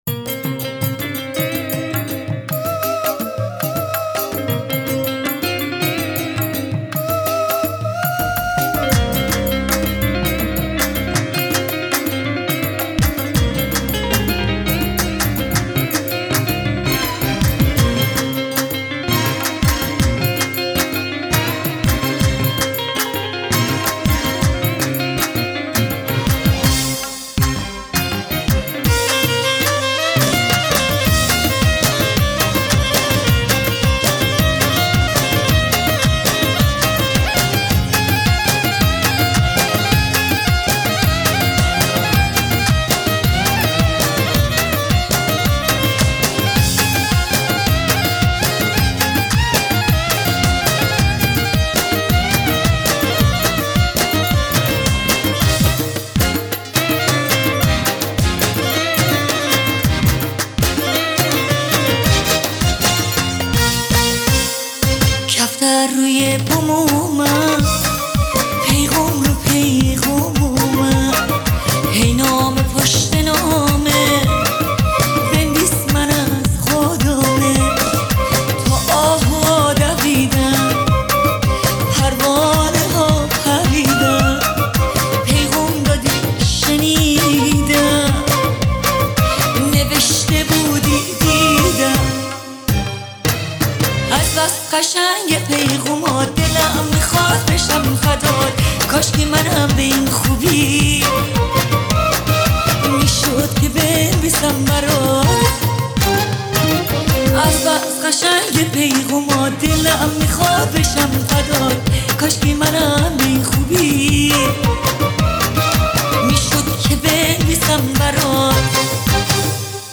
با صدای مرد